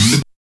84 SCRATCH.wav